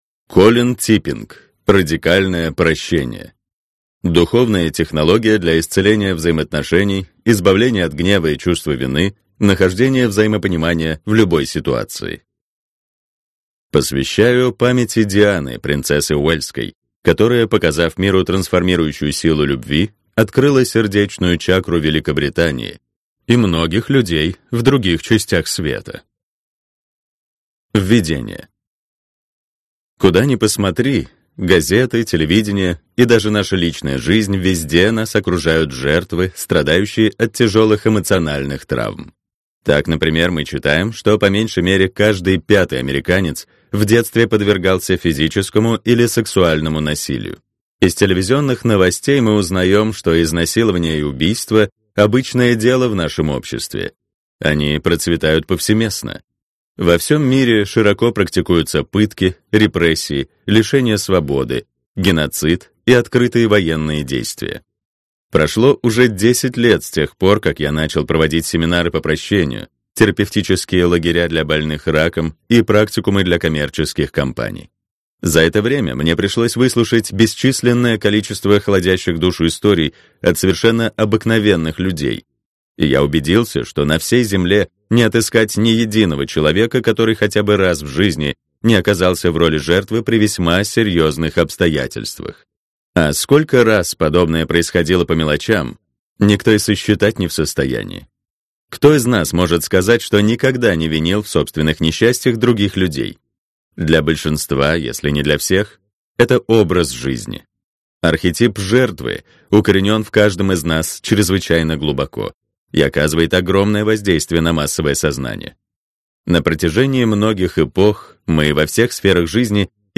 Аудиокнига Радикальное Прощение.